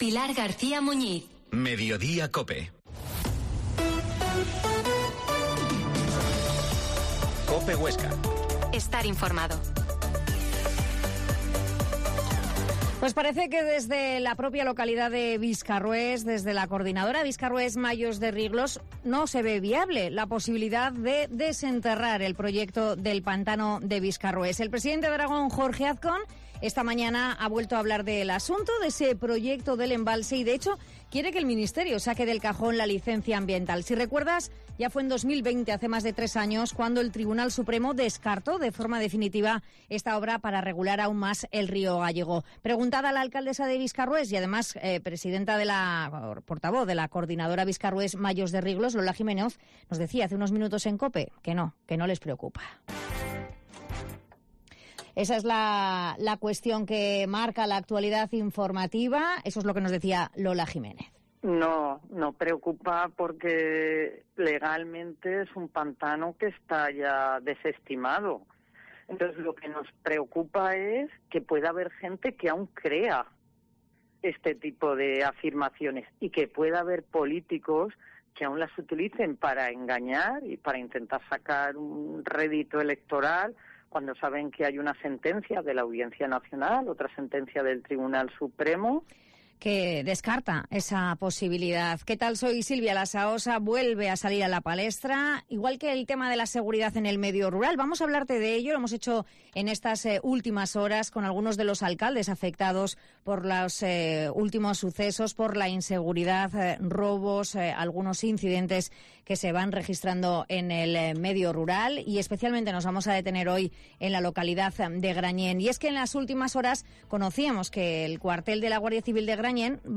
Mediodia en COPE Huesca 13.50 Entrevista al alcalde de Grañén, Carlos Samperiz